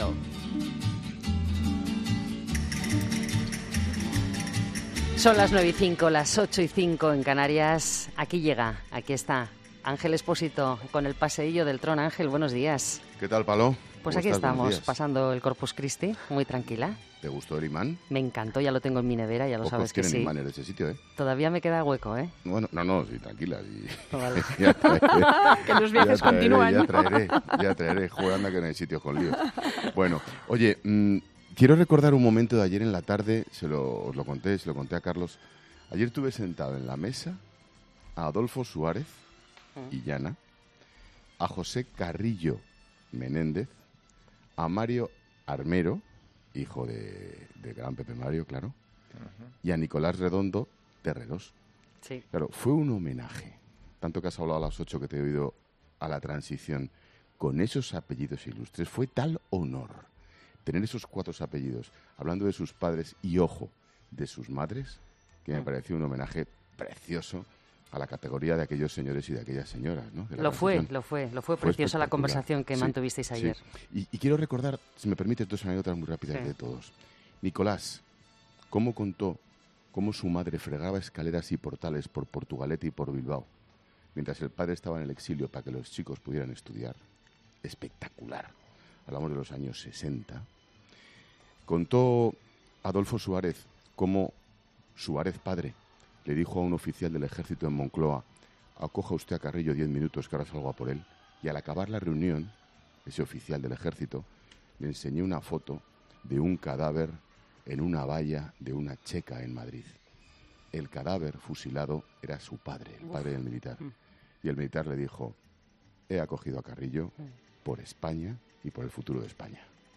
La tertulia de la Transición en 'La Tarde', en 'El paseíllo del Tron' con Ángel Expósito.